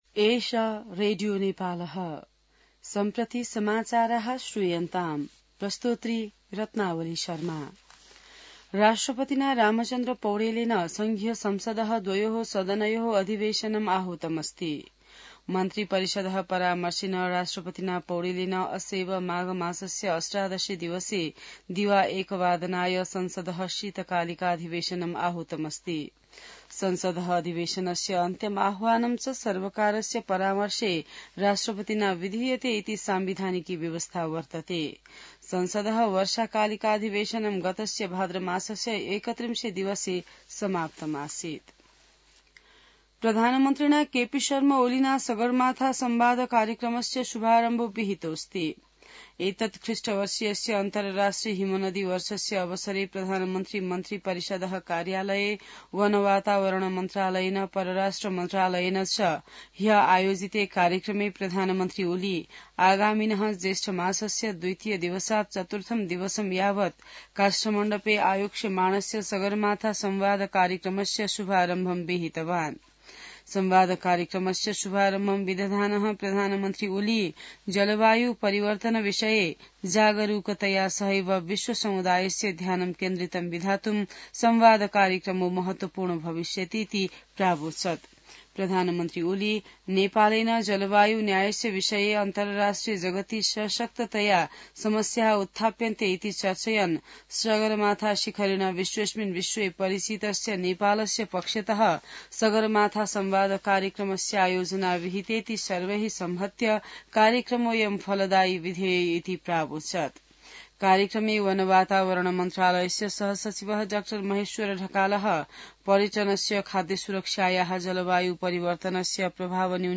संस्कृत समाचार : १० माघ , २०८१